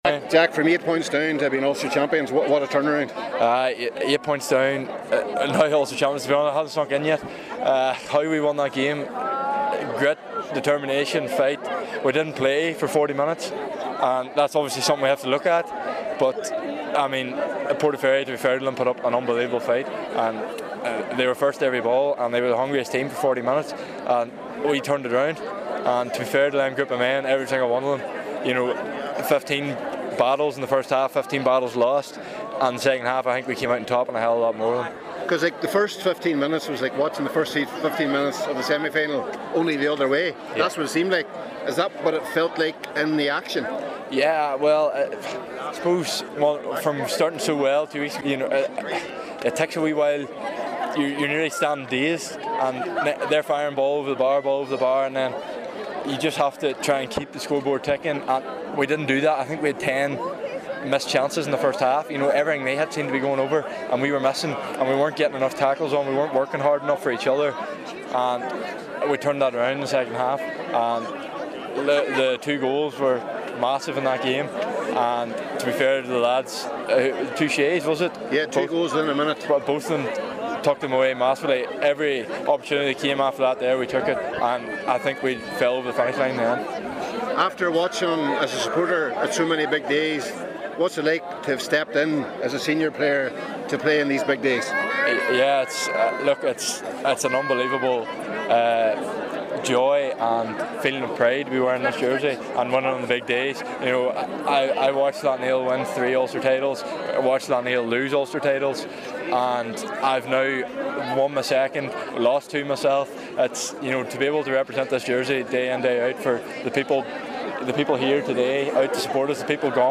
After the game